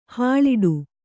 ♪ hāḷiḍu